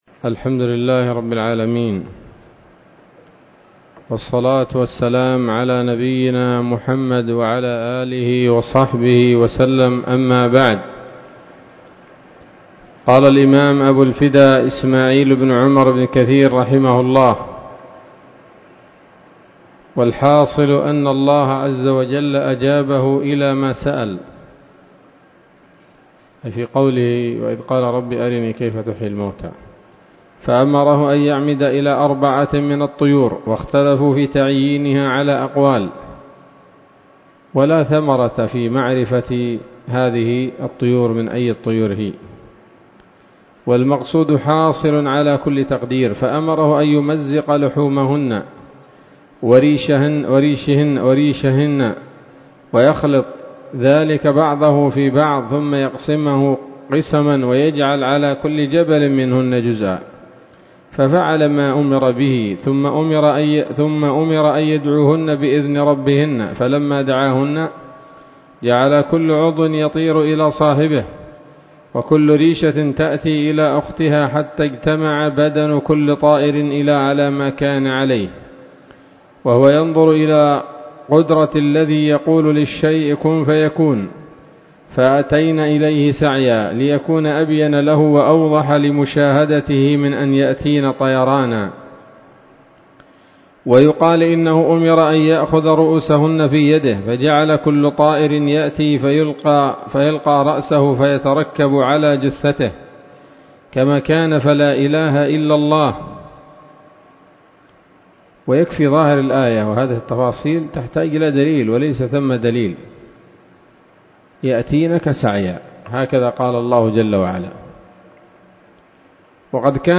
الدرس الثالث والخمسون من قصص الأنبياء لابن كثير رحمه الله تعالى